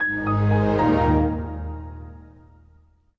ope, my brain just made the Windows XP shutdown sound. guess i should think about dinner